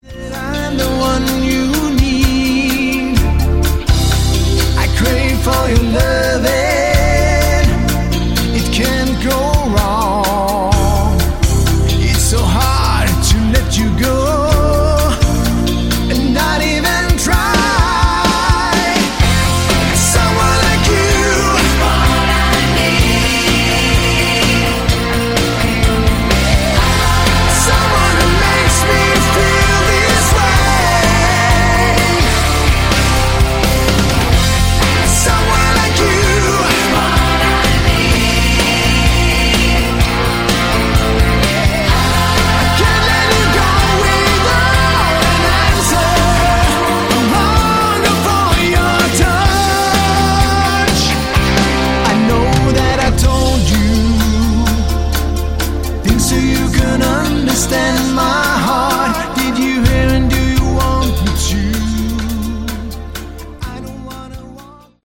Category: Scandi AOR
Smooth vocals, choruses, lots of keys and subtle guitars.